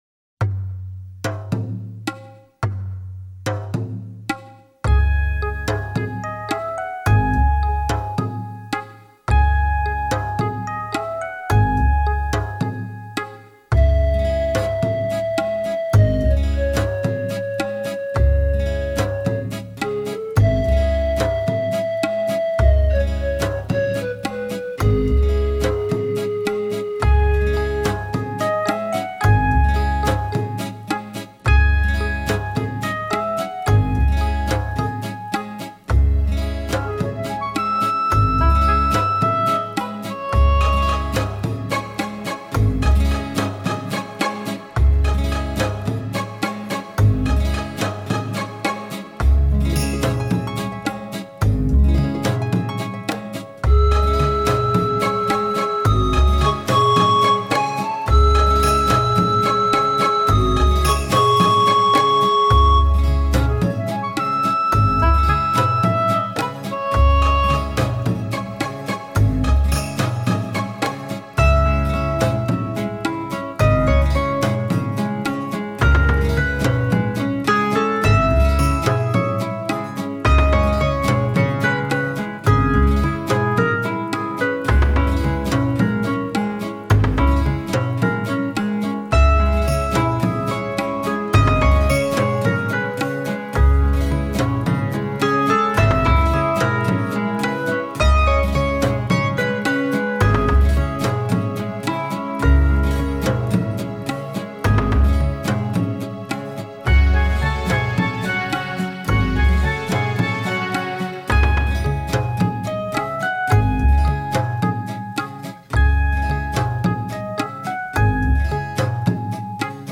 希腊音乐
作品有极其丰富的旋律，澎湃的节奏，干净鲜亮的音色，饱满的和声，极具欣赏性。